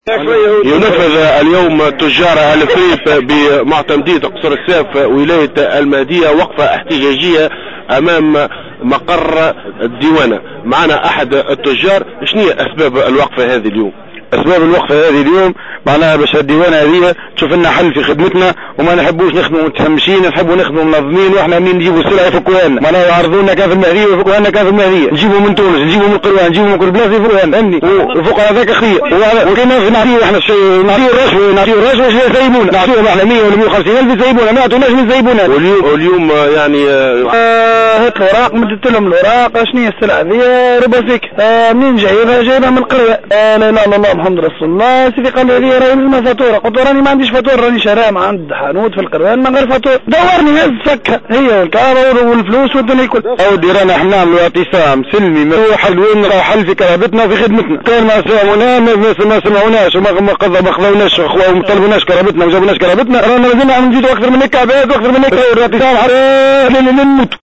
وأكد عدد من المحتجين لمراسل جوهرة "اف ام" أنهم يتعرضون لعديد التضييقات من قبل أعوان الديوانة الذين يعطلون مصالحهم للحصول على الرشوة على حد قوله.